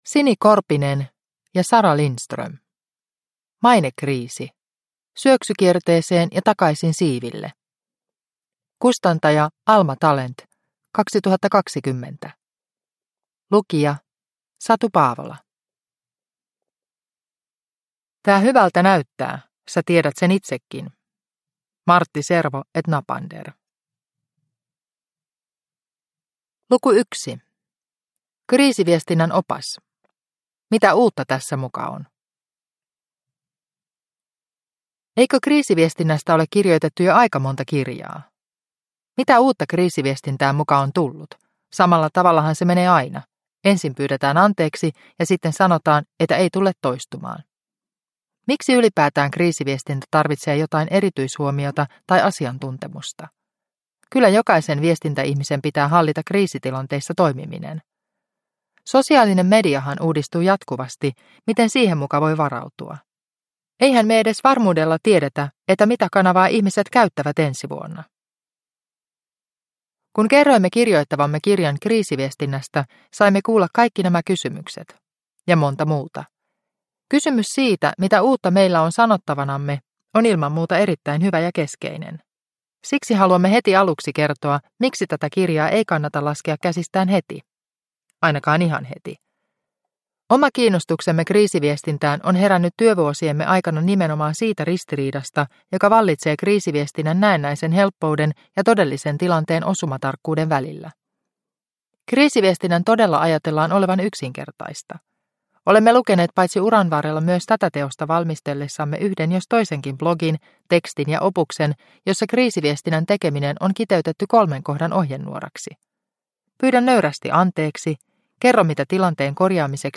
Mainekriisi – Ljudbok – Laddas ner